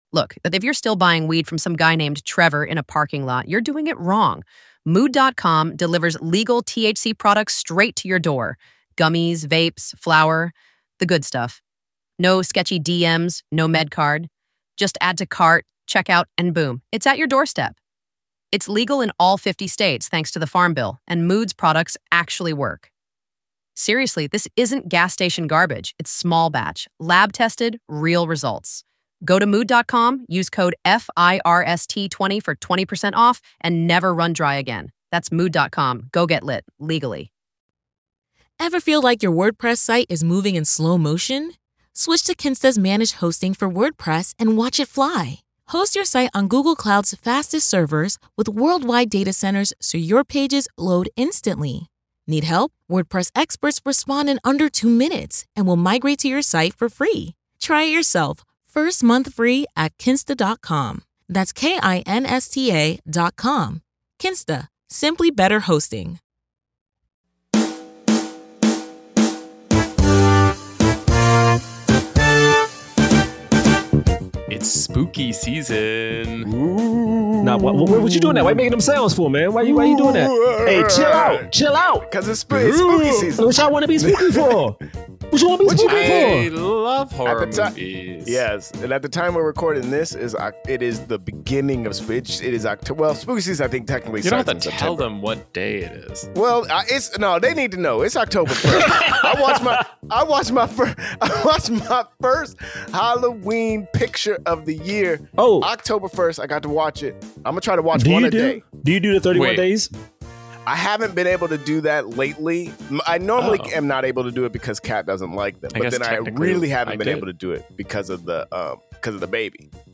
Black Men Can't Jump [In Hollywood] is a comedic podcast that reviews films with leading actors of color and analyzes them in the context of race and Hollywood's diversity issues.